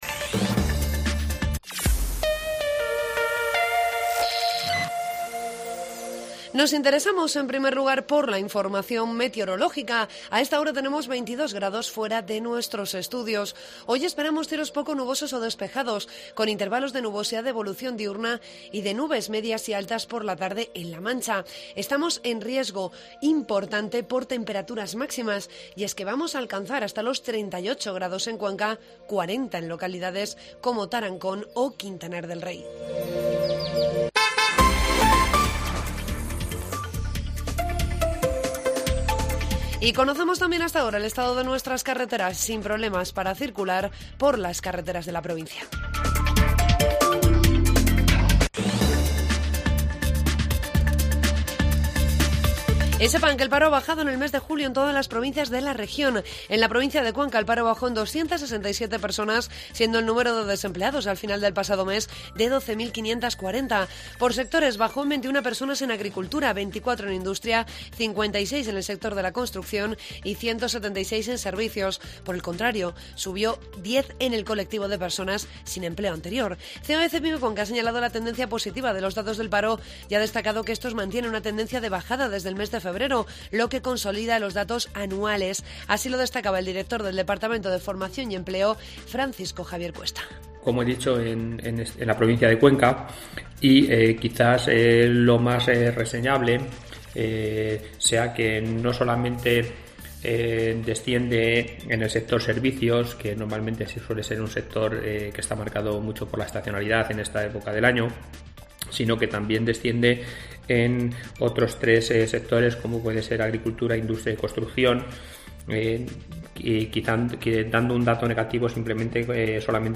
Informativo matinal COPE Cuenca 3 de agosto